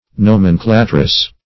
Nomenclatress \No"men*cla`tress\, n. A female nomenclator.
nomenclatress.mp3